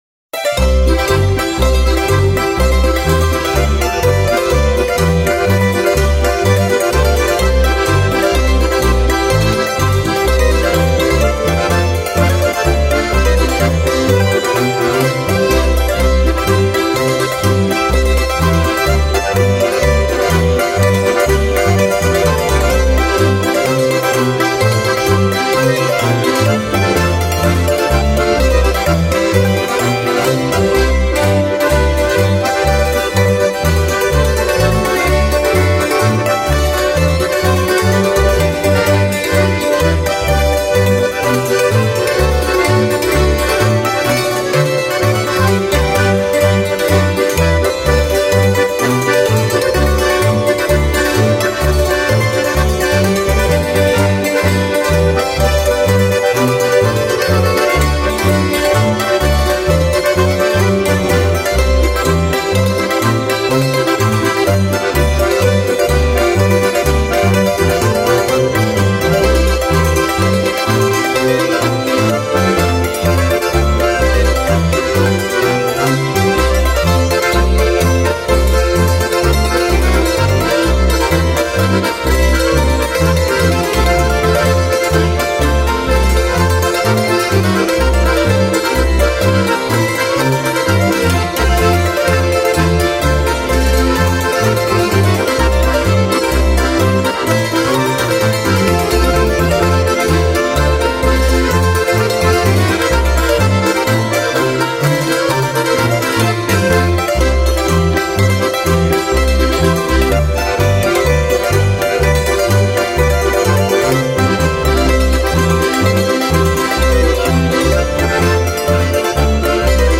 Ländlertrio Wilti-Gruess